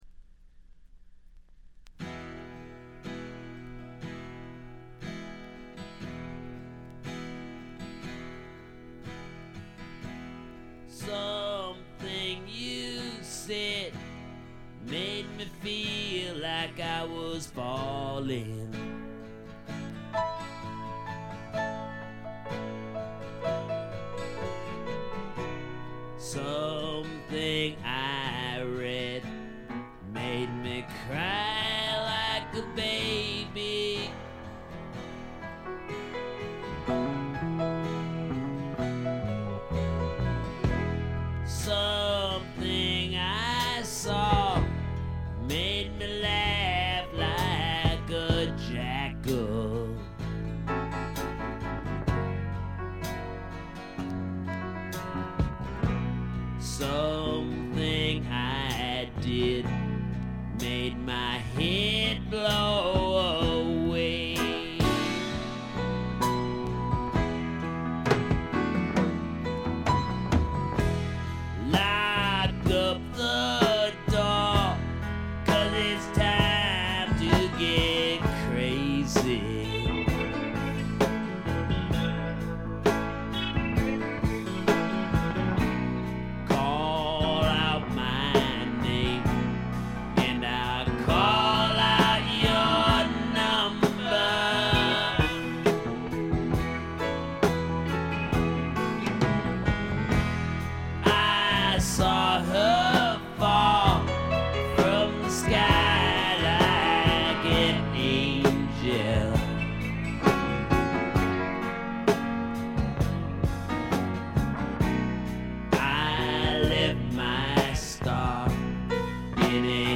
部分試聴ですが、ところどころで軽微なチリプチ、散発的なプツ音少し。
試聴曲は現品からの取り込み音源です。